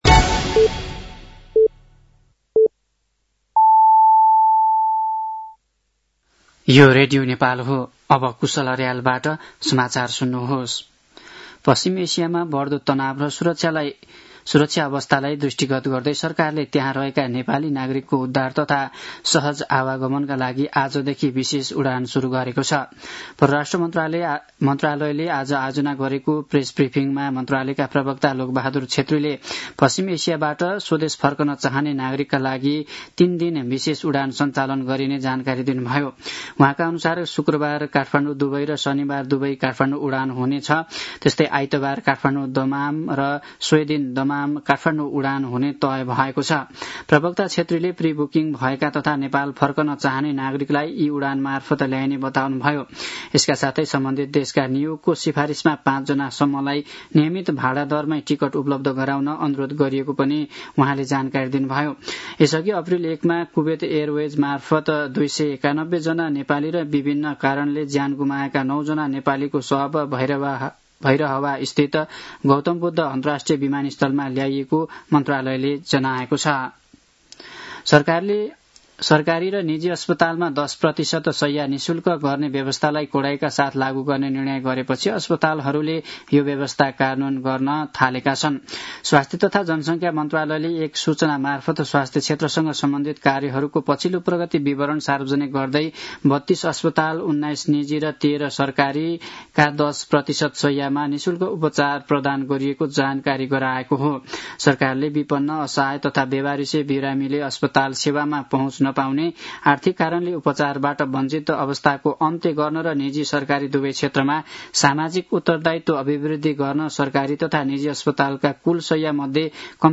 साँझ ५ बजेको नेपाली समाचार : २० चैत , २०८२
5-pm-nepali-news-12-20.mp3